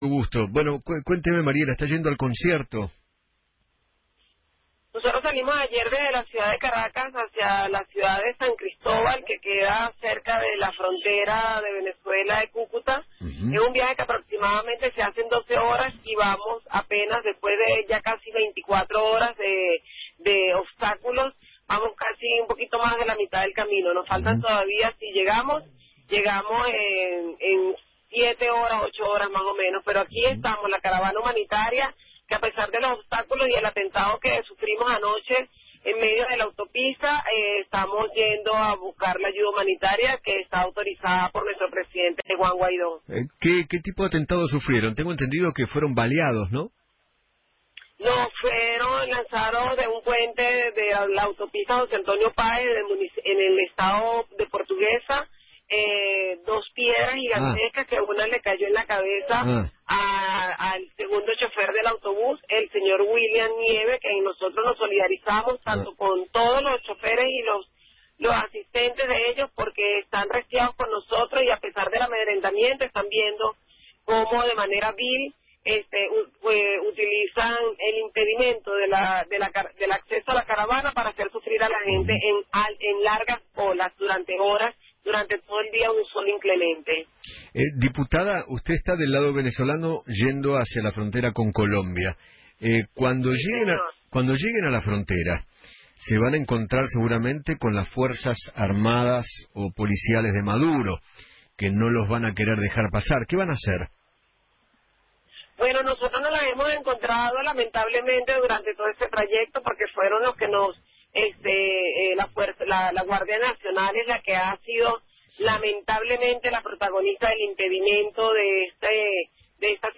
Mariela Magallanes, Diputada de la Asamblea de Venezuela, habló en Feinmann 910 y dijo que “Salimos ayer de la Ciudad de Caracas a la ciudad de Cúcuta.